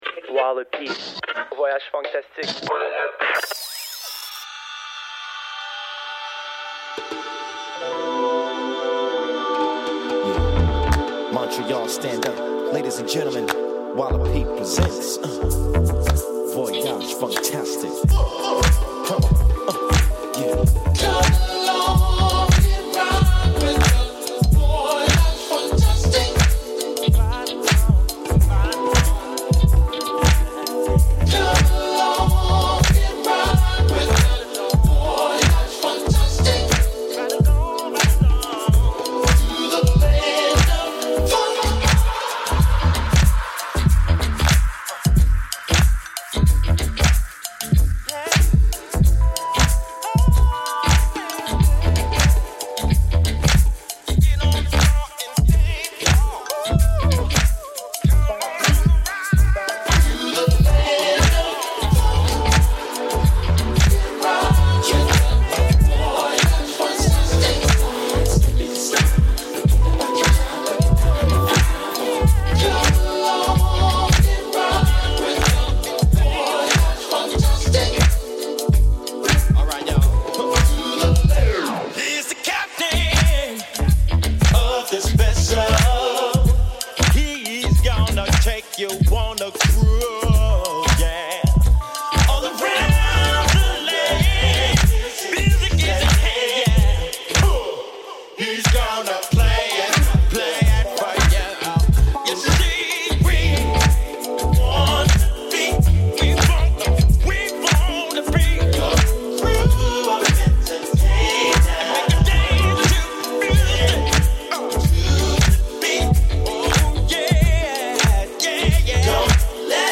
blessed us with a 45 minutes Modern Funk (all-vinyl) set.